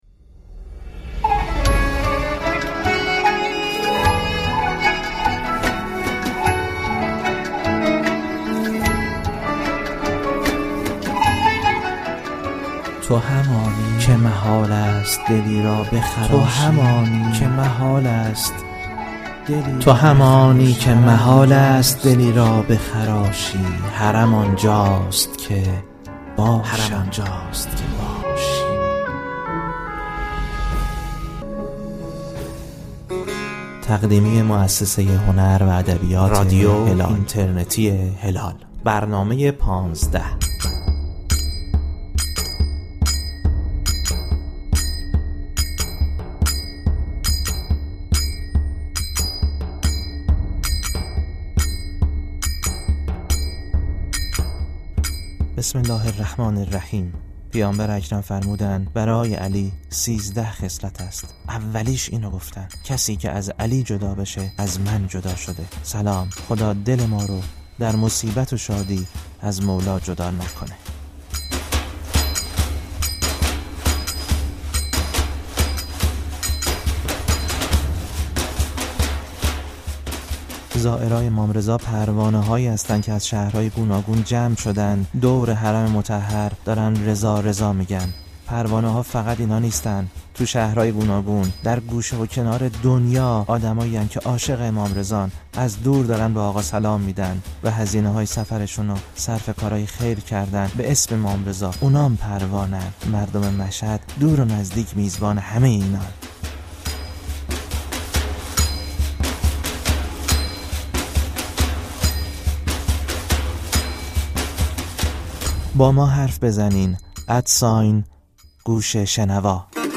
در سیزدهمین قسمت از مجموعه «پانزده»، با صدای زائران پیاده مشهد همراه می‌شویم؛ اینان نه با پا، که با نذر آمدند و هر قدم‌شان، یک سلام از دور به ضامن آهو است.